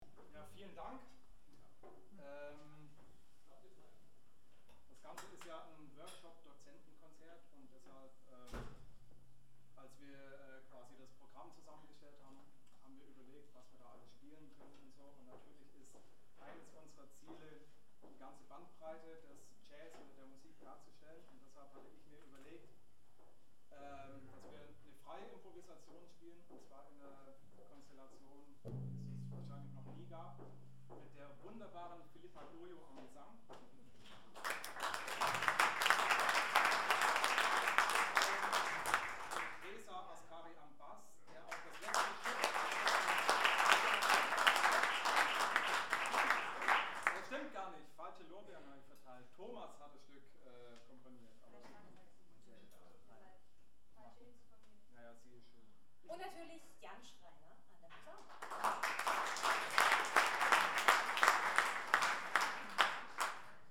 06 - Ansage.mp3